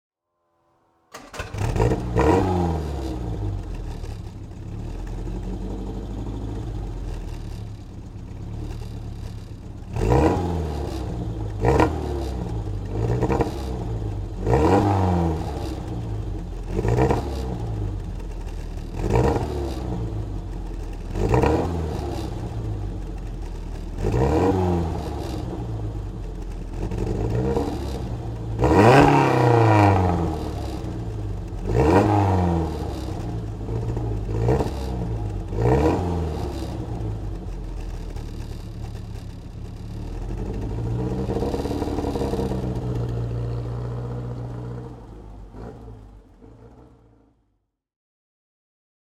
Lotus Elan Sprint DHC (1972) - Starten und Leerlauf